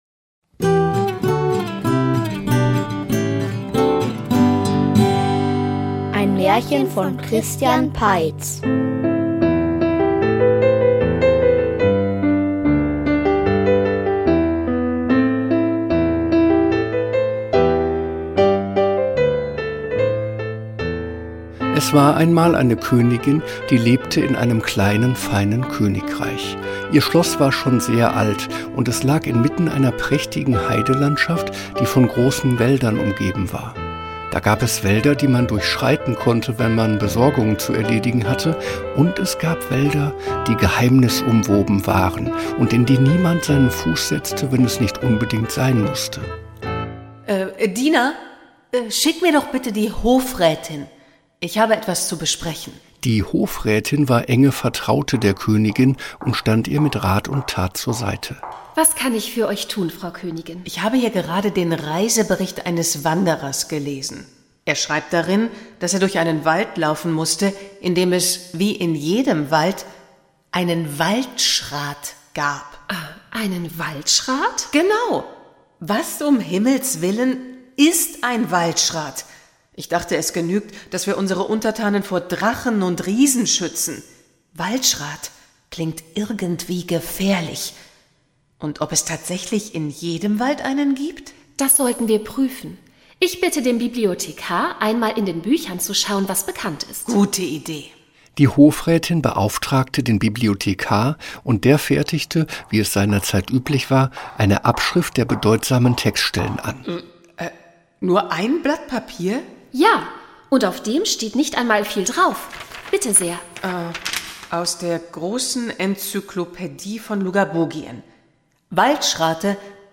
Der Märchenprinz --- Märchenhörspiel #37 – Märchen-Hörspiele – Podcast